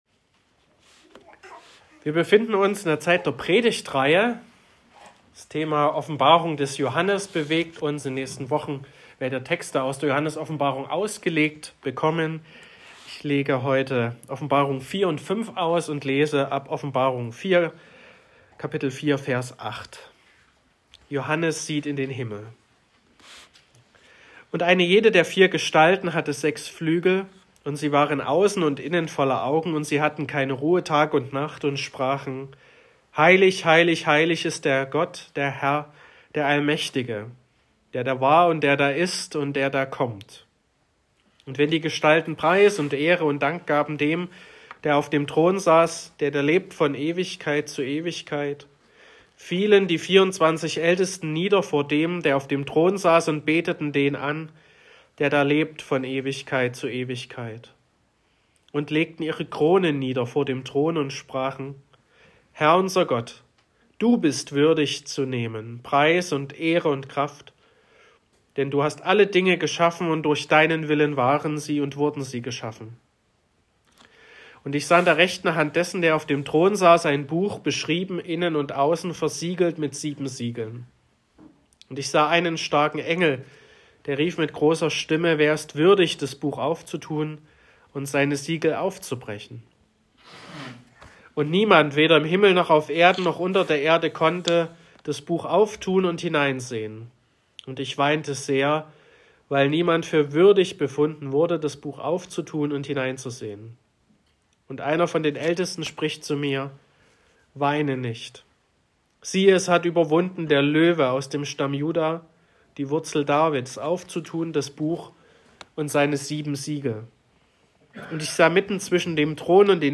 14.01.2024 – Gottesdienst
Predigt und Aufzeichnungen